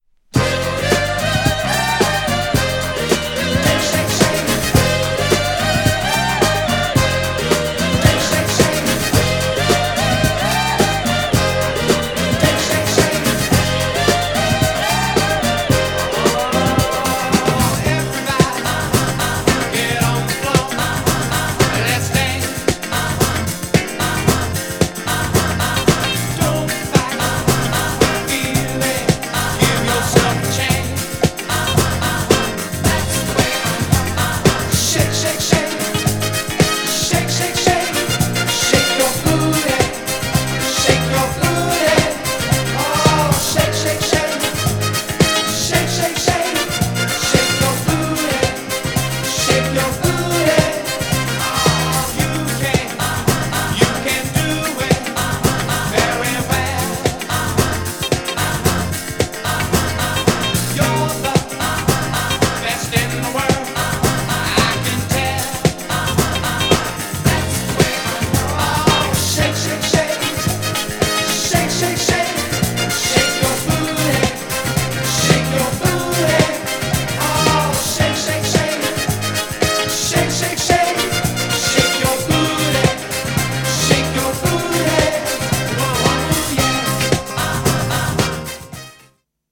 GENRE Dance Classic
BPM 111〜115BPM